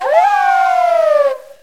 uncomp_lycanroc_midnight.aif